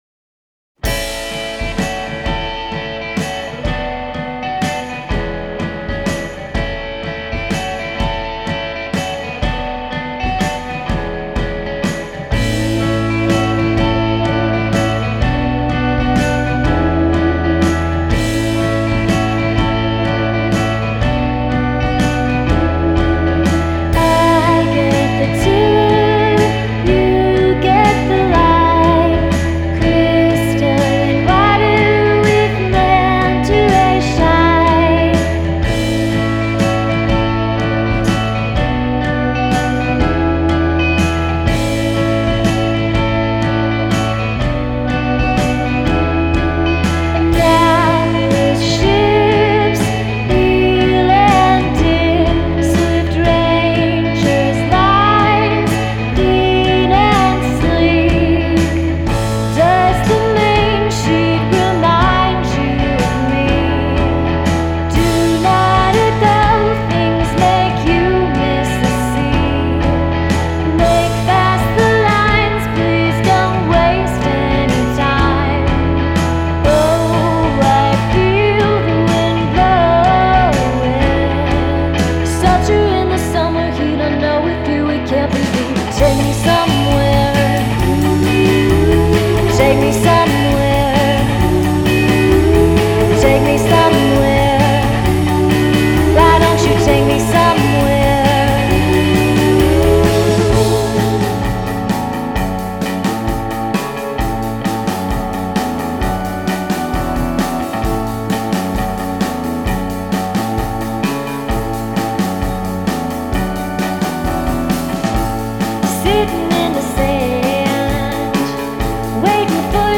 cullati dalle onde calde e da un ritmo surf